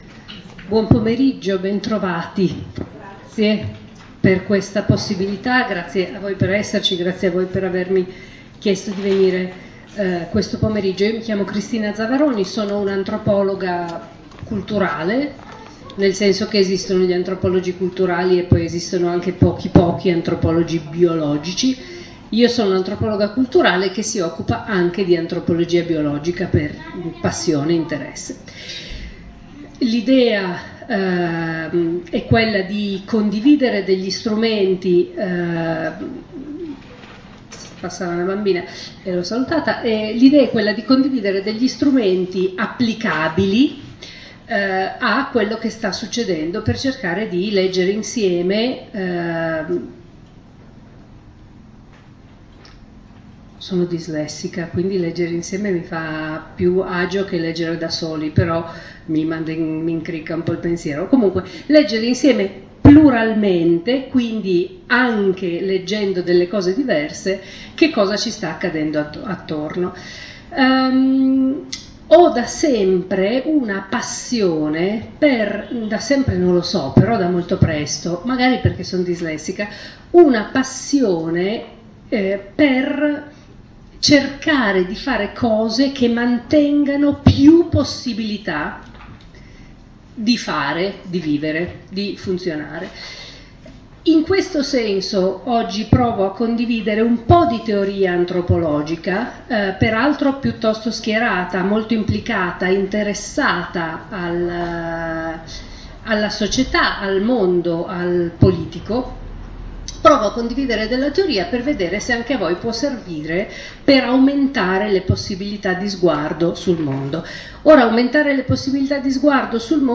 Appuntamento pubblico per cercare di analizzare quello che stiamo vivendo con uno sguardo generale e complessivo sulla pandemia e le sue conseguenze.
INTERVENTO